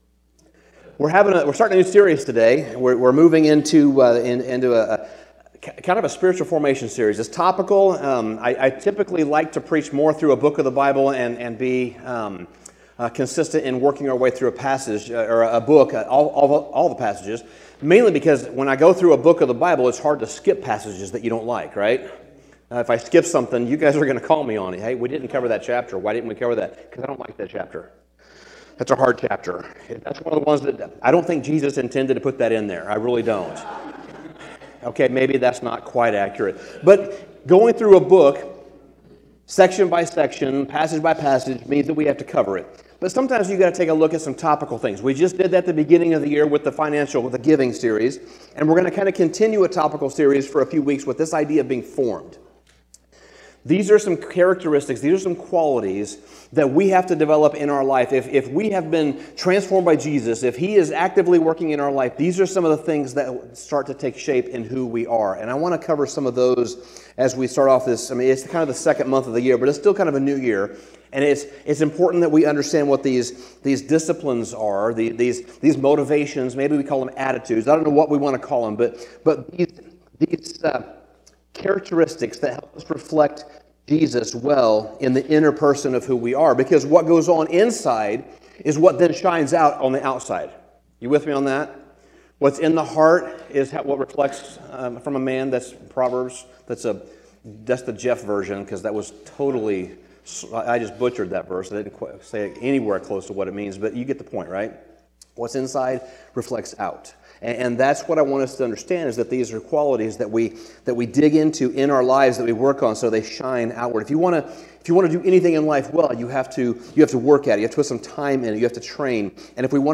Sermon Summary To be formed from the inside out means to have a heart for the people around us.